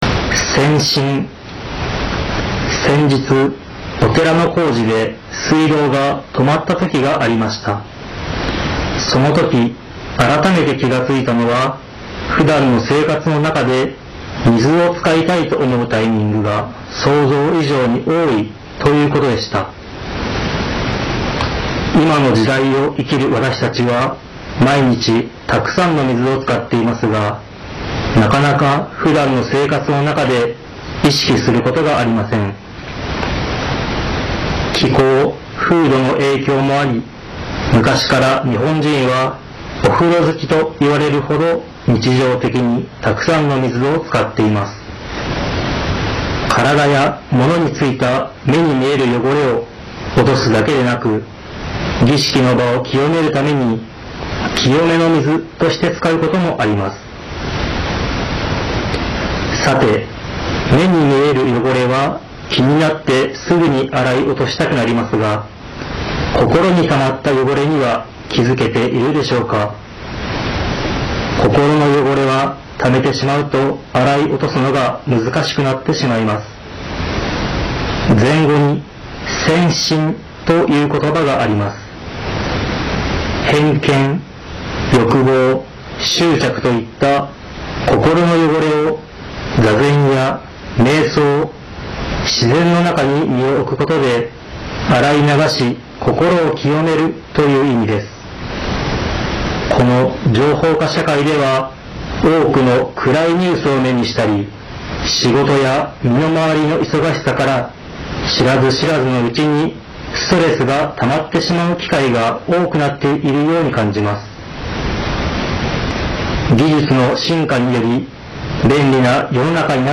テレフォン法話
曹洞宗岐阜宗務所では電話による法話の発信を行っています。